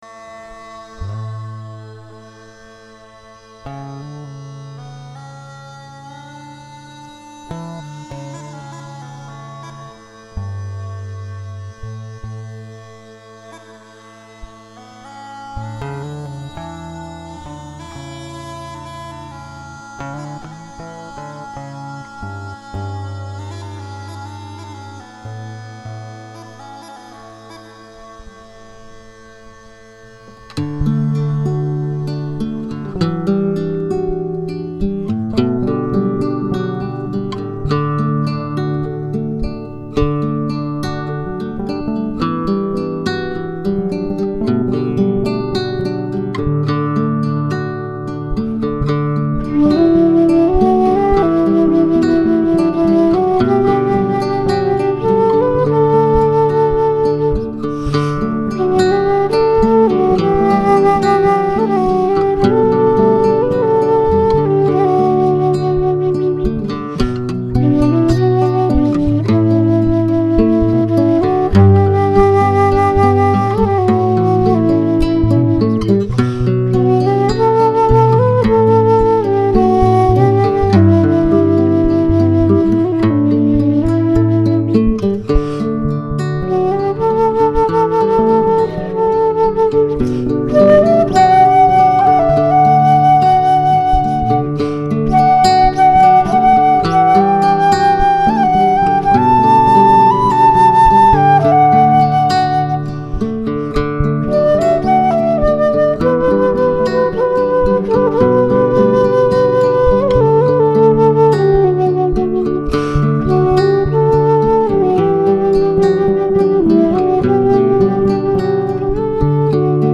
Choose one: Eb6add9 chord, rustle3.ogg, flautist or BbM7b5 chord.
flautist